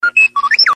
Categoria Messaggio